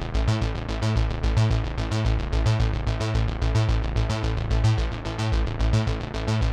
Index of /musicradar/dystopian-drone-samples/Droney Arps/110bpm
DD_DroneyArp4_110-A.wav